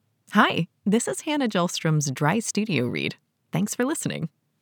Female
Authoritative, Bright, Corporate, Engaging, Friendly, Natural, Reassuring, Warm
Neutral American English (native)
Audio equipment: Professional home studio, Universal Audio Spark interface